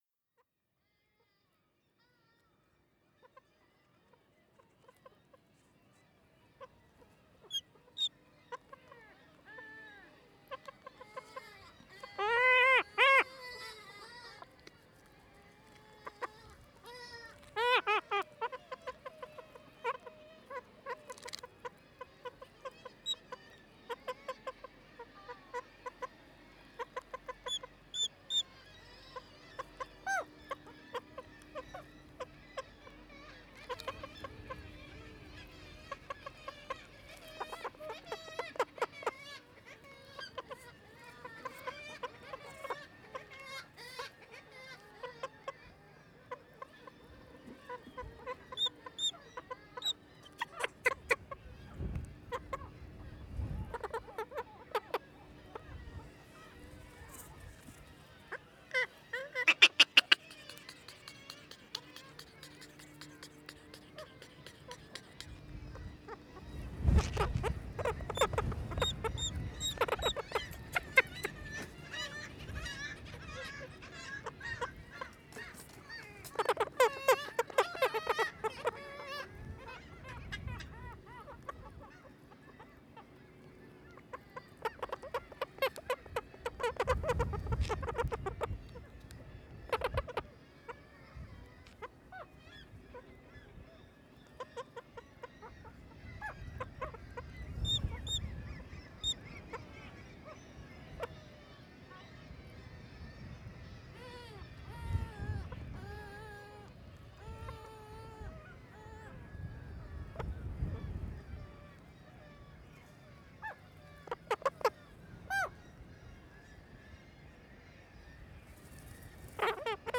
Field Recording Series by Gruenrekorder
The latter’s ‘voice‘ is particularly unusual, a warble and wail that at certain moments resembles a whimper or even laughter; they appear in “The birdcliffs of Vedøy” too, though this time as a shrieking, engulfing mass.
The recording at times gets extremely close to the birds.
Gruen_204___The_Kittiwakes_Of_Kårøya.mp3